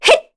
Veronica-Vox_Attack1_kr.wav